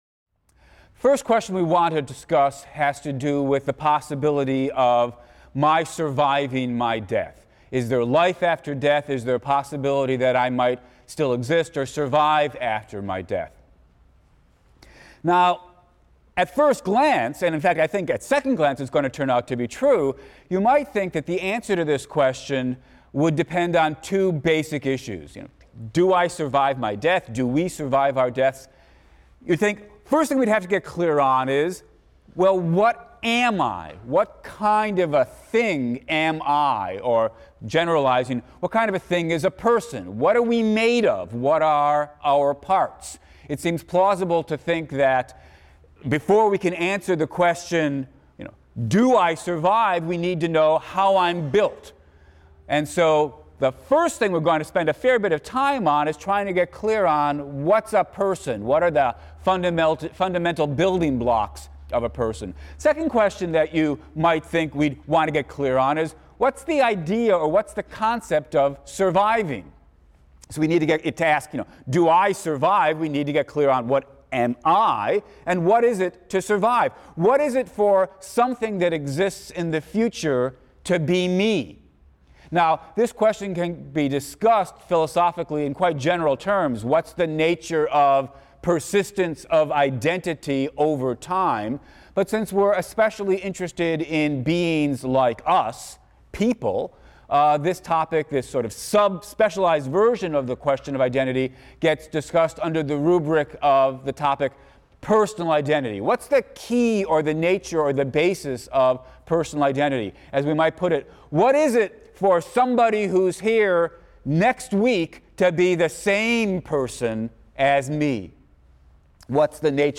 PHIL 176 - Lecture 2 - The Nature of Persons: Dualism vs. Physicalism | Open Yale Courses